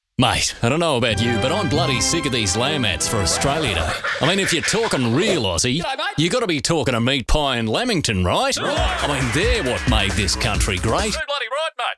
Award-winning Australian Voice. versatile, clear, and seriously experienced.
• Tradie
• Professional Voice booth – acoustically treated.